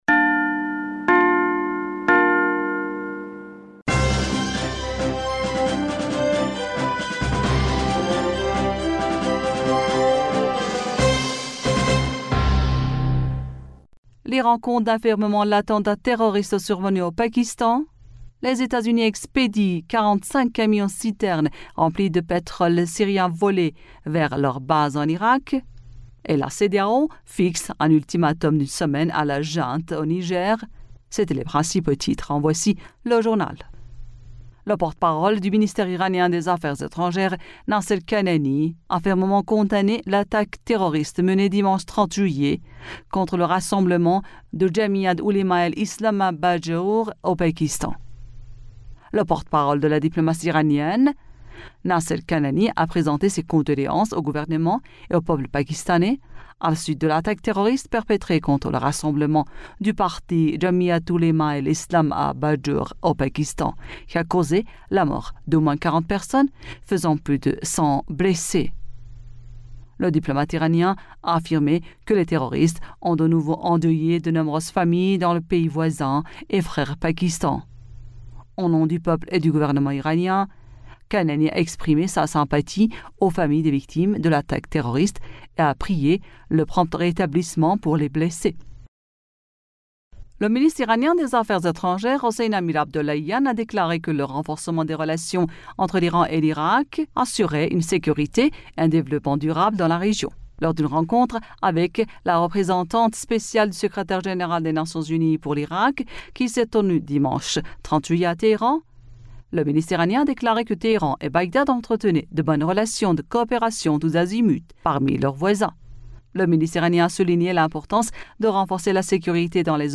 Bulletin d'information du 31 Juillet 2023